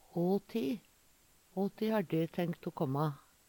hå ti - Numedalsmål (en-US)